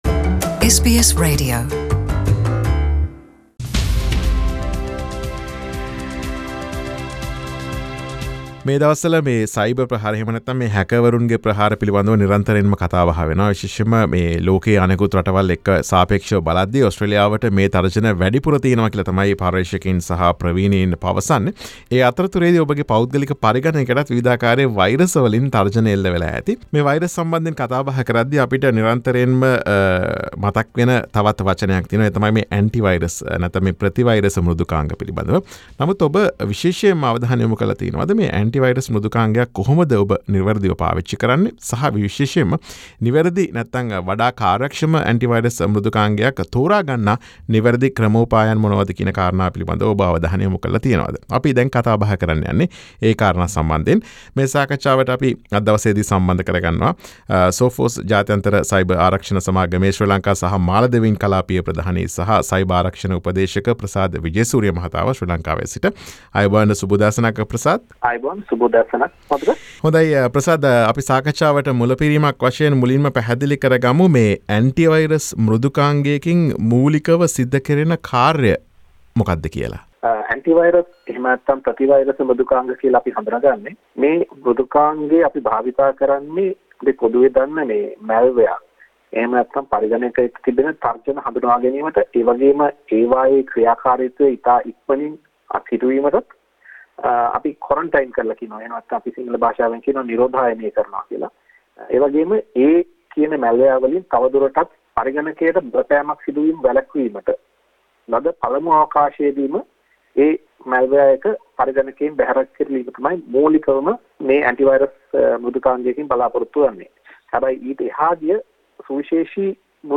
සාකච්ඡාව.